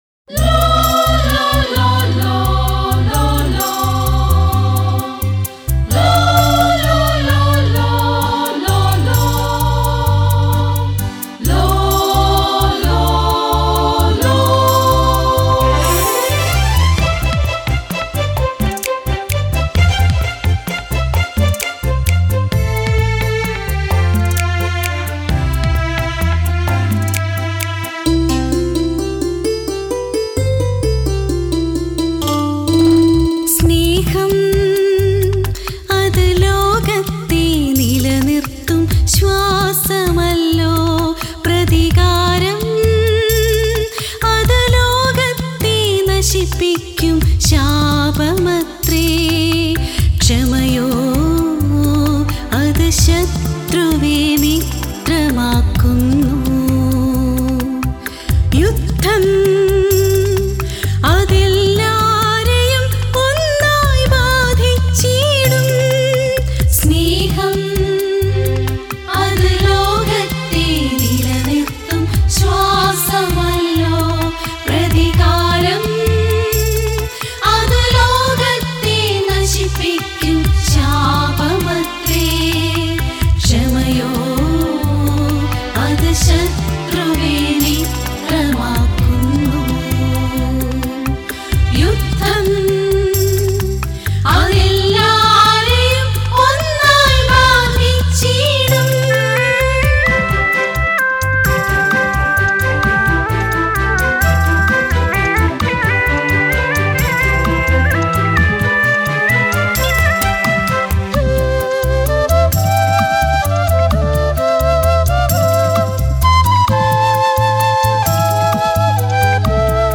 Keyboard Sequence
Rhythm Sequence
Flute
Tabala & Percussion